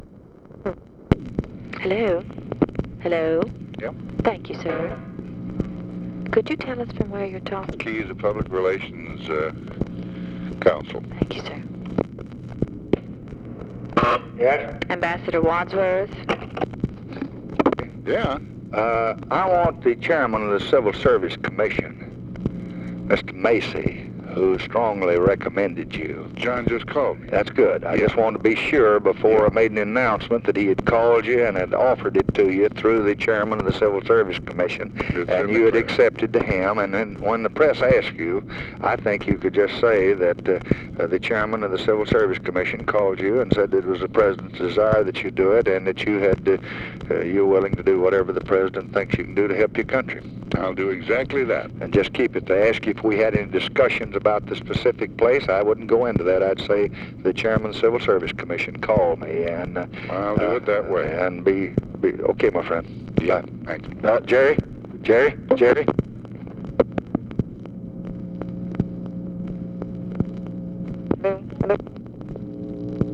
Conversation with JAMES WADSWORTH and OFFICE SECRETARY, March 25, 1965